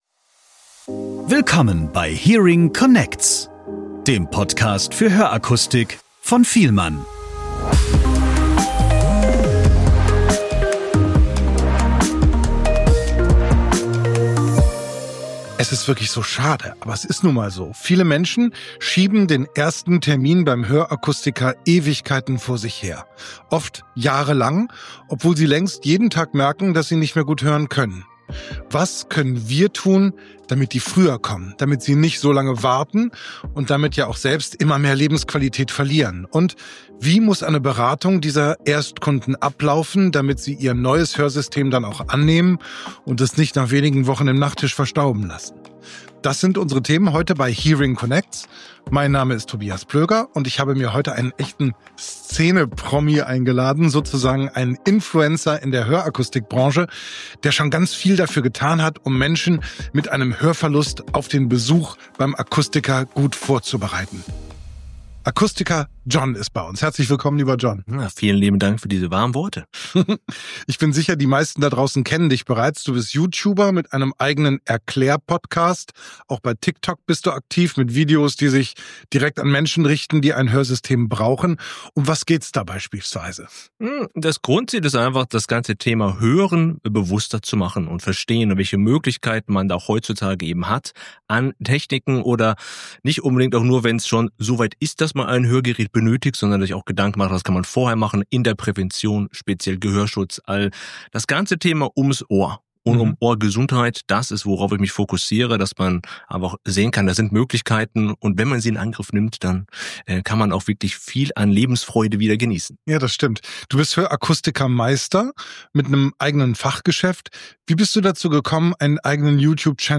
Das Expertengespräch zeigt auch, wie wichtig unabhängige Aufklärung ist, um realistische Erwartungen zu fördern und langfristige Zufriedenheit zu ermöglichen.